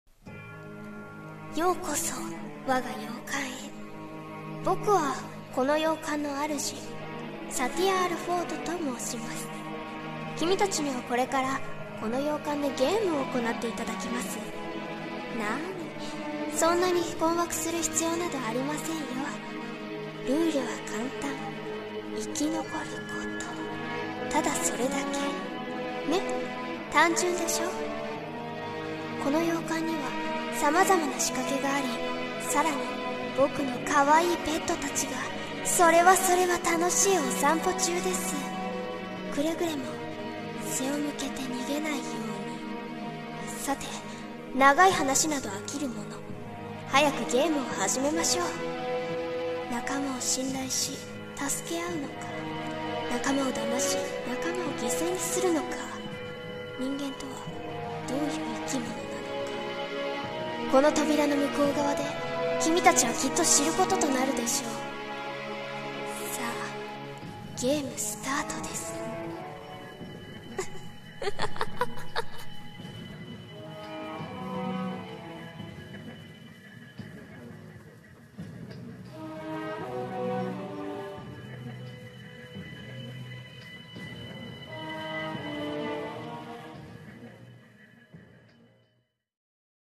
朗読.セリフ【人間ゲーム】声劇